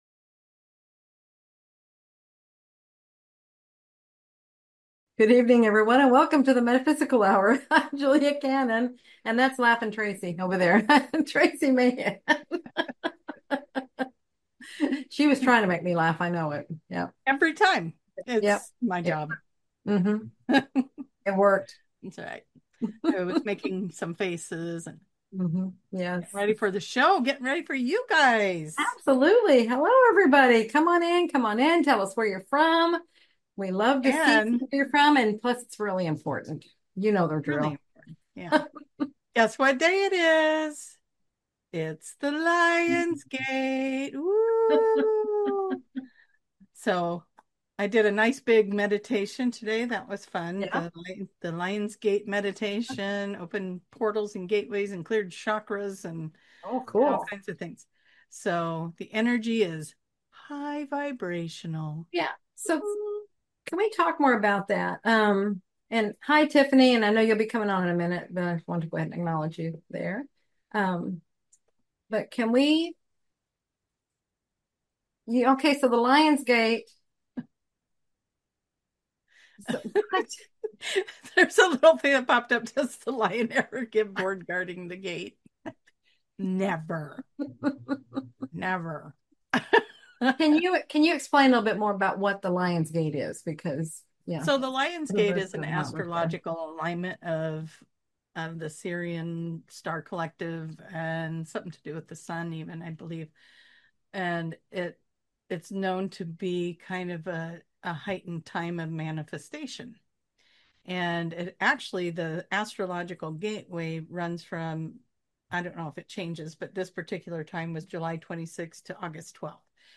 Answering viewer questions